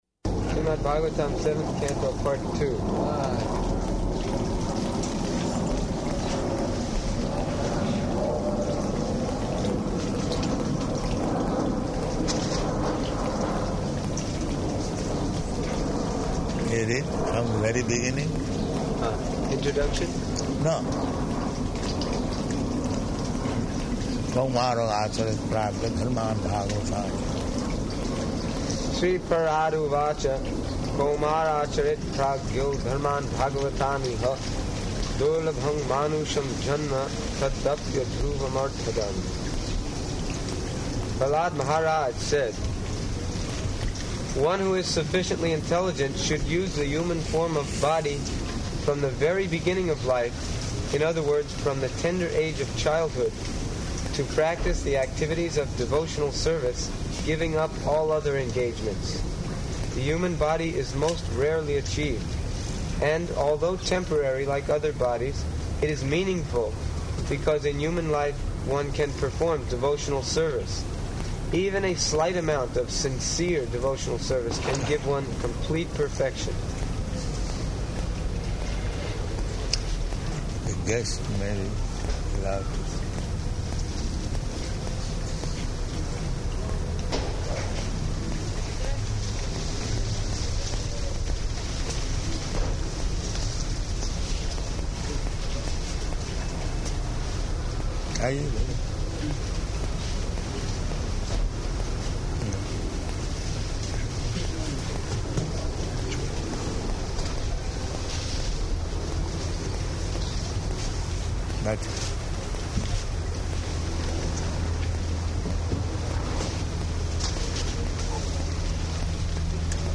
Garden Conversation
-- Type: Conversation Dated: June 9th 1976 Location: Los Angeles Audio file